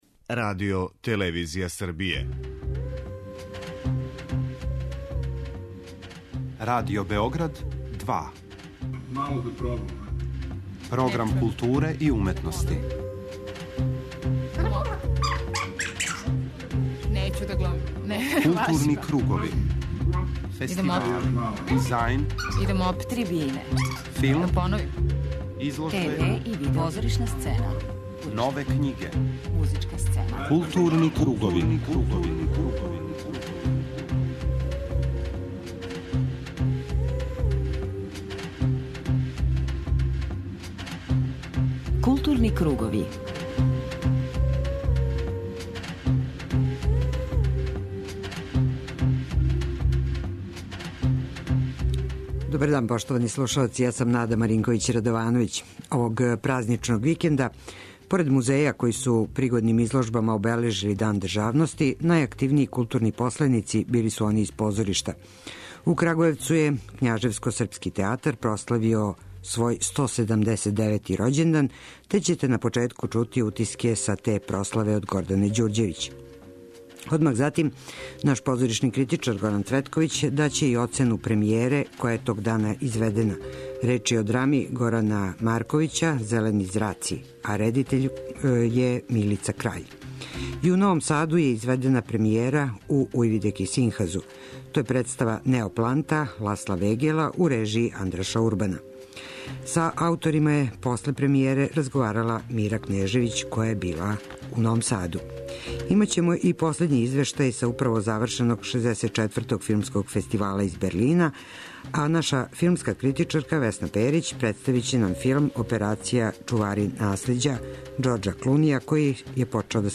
У првом делу eмисије објавићемо разговор са уметницима који су извели премијеру представе 'Неопланта' у Новосадском позоришту (Ујвидеки Синхаз), као и критику ове представе.
преузми : 53.20 MB Културни кругови Autor: Група аутора Централна културно-уметничка емисија Радио Београда 2.